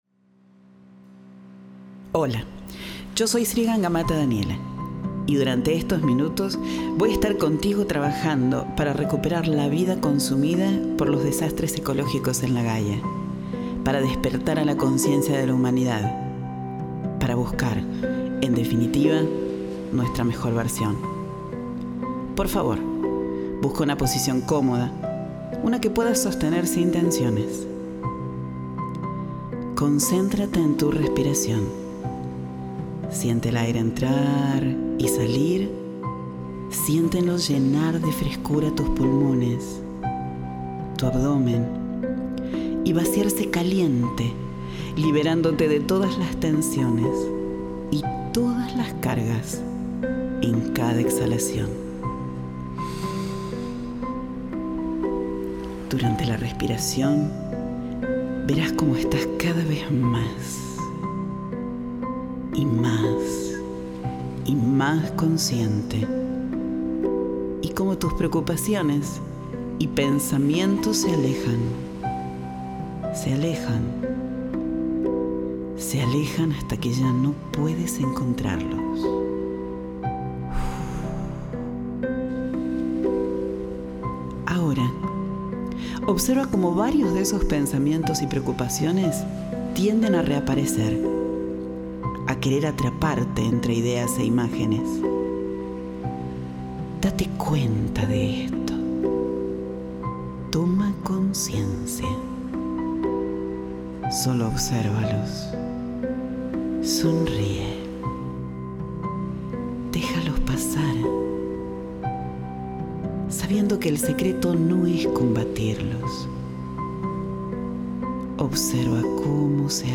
MEDITACIÓN PARA SANAR LA TIERRA · SGM Coaching